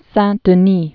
(săɴ-də-nē)